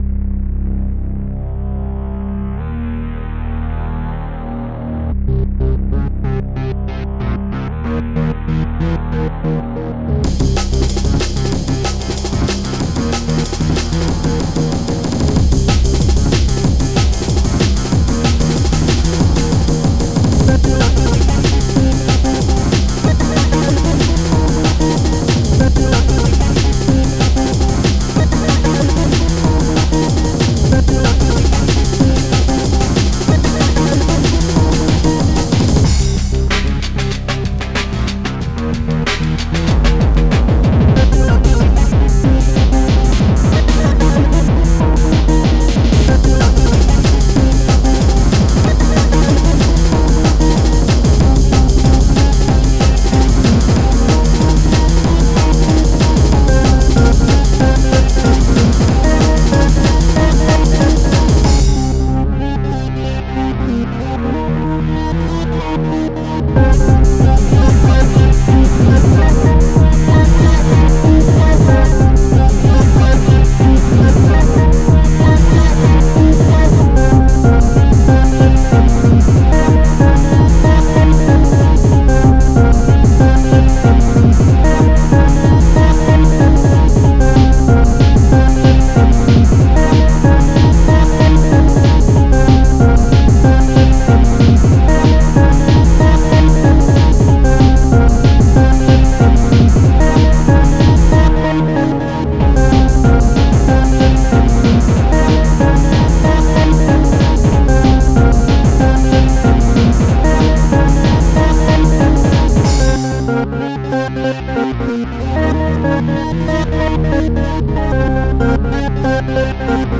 style: gabba/techno mix